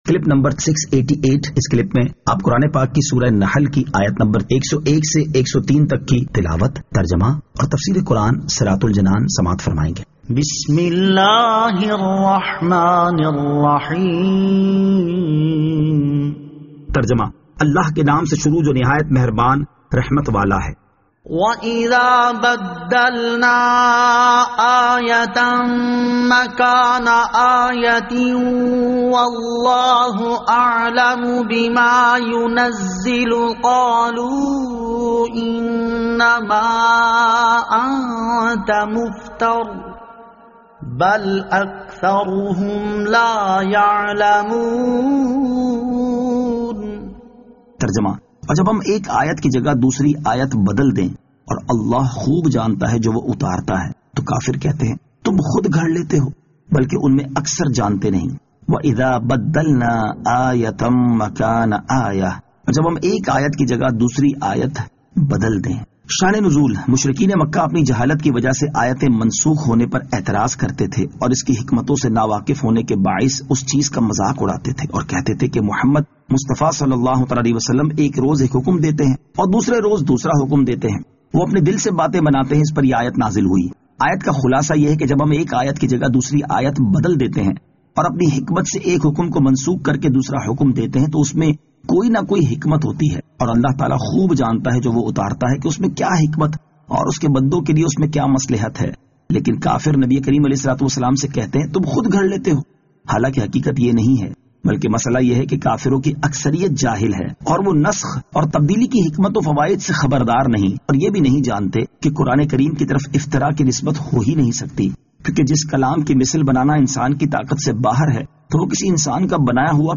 Surah An-Nahl Ayat 101 To 103 Tilawat , Tarjama , Tafseer